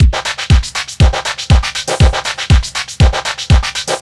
NRG 4 On The Floor 026.wav